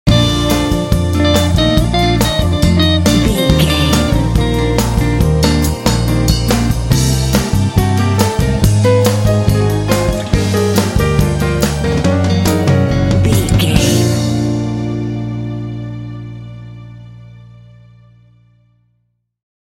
This jazzy track is ideal for board games and main menus.
Aeolian/Minor
B♭
mellow
sensual
cool
piano
electric guitar
bass guitar
strings
modern jazz